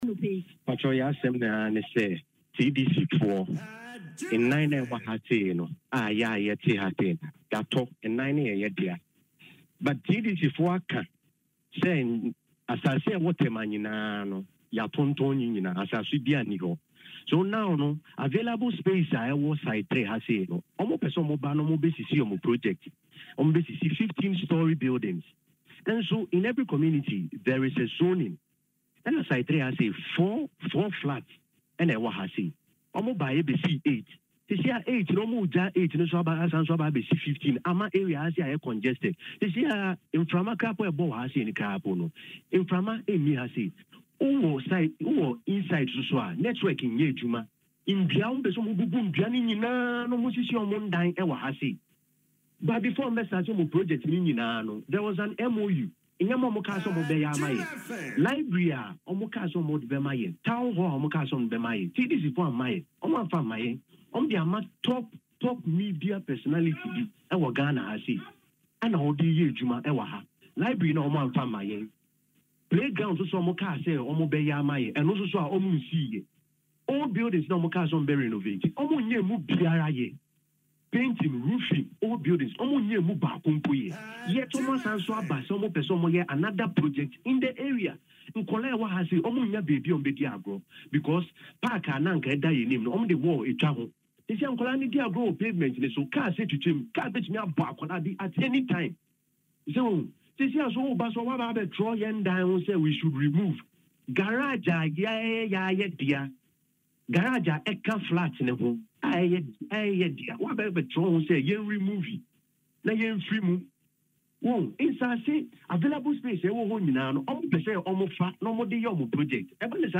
In an interview on Adom FM’s Dwaso Nsem, the residents expressed outrage over the situation.